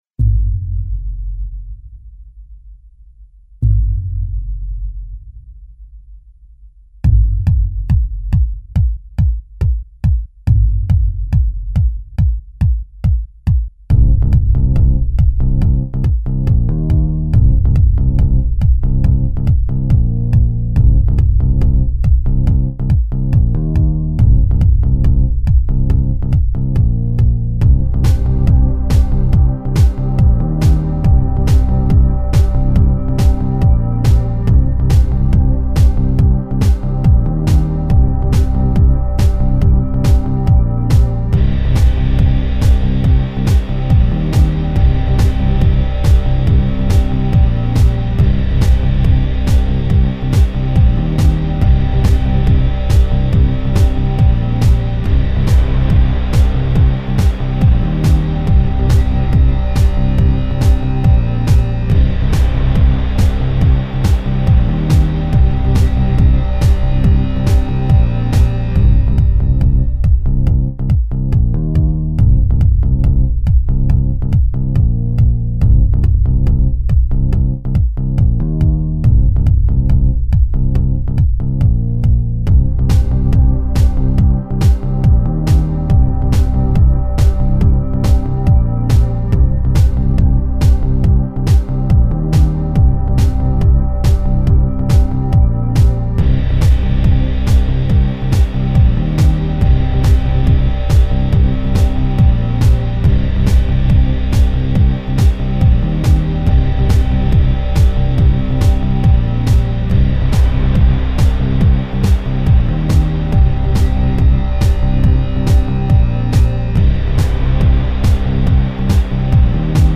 Bass Heavy Tech.